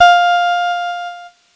Cheese Note 11-F3.wav